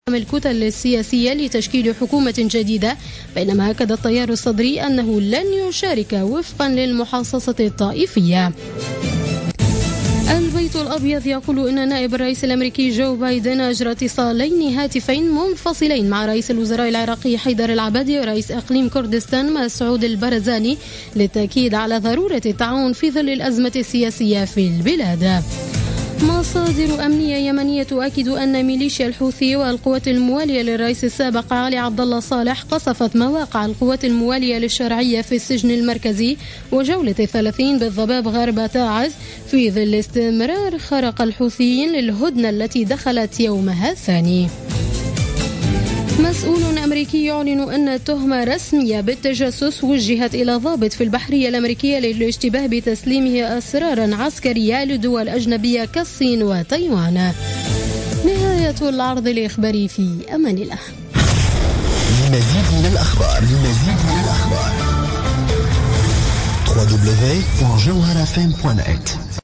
نشرة أخبار منتصف الليل ليوم الثلاثاء 12 أفريل 2016